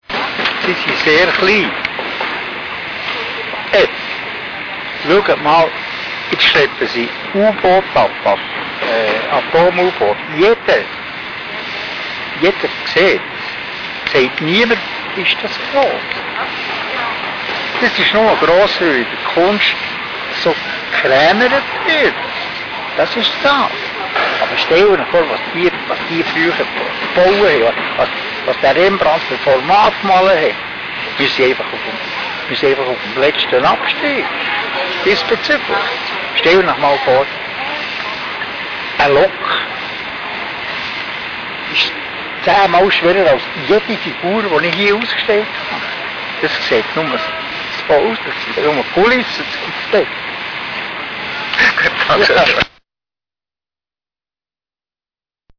(Entschuldigt bitte die schlechte Tonqualität, aber damals arbeiteten wir, wie auf dem Bild zu sehen ist, mit analogen Behelfsgerätschaften).